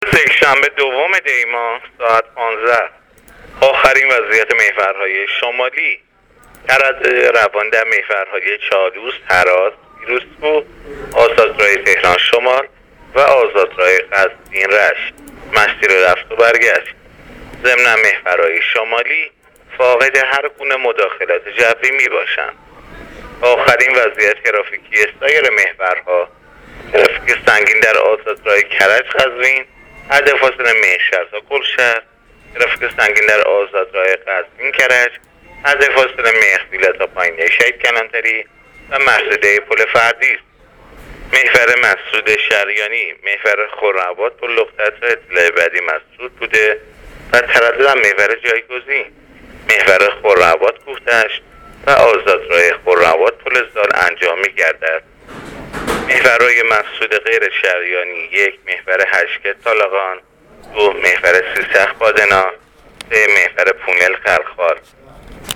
گزارش رادیو اینترنتی از آخرین وضعیت ترافیکی جاده‌ها تا ساعت ۱۵ دوم دی؛